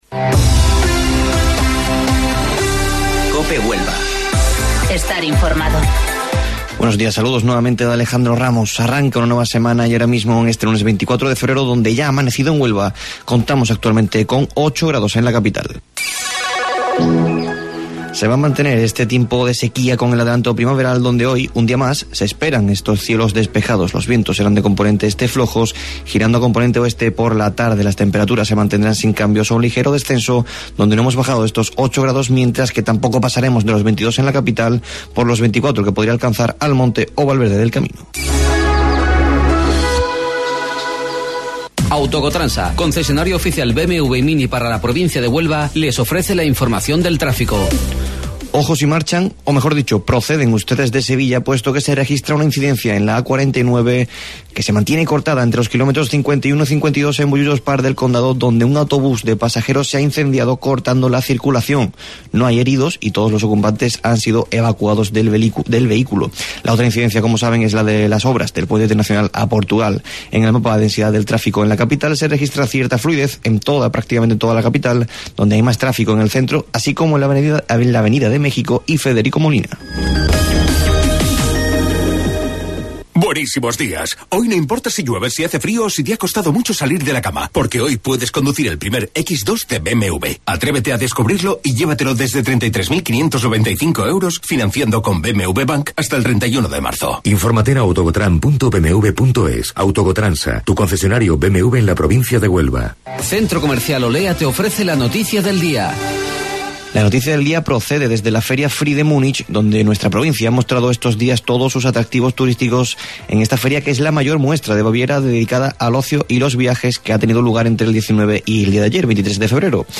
AUDIO: Informativo Local 08:25 del 24 Febrero